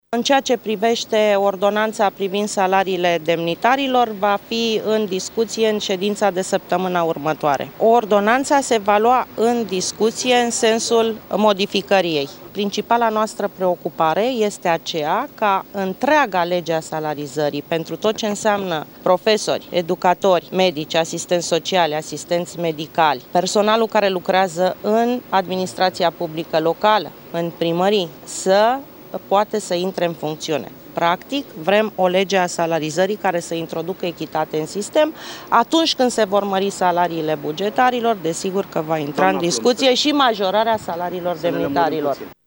Așa a declarat în urmă cu puțin timp președintele interimar al PSD, Rovana Plumb la ieşirea de la ședința coaliției.
De asemenea, Rovana Plumb a mai spus că ordonanța privind salariile demnitarilor a fost amânată pentru săptămâna viitoare, ea va fi modificată și nu se va aplica de luna viitoare: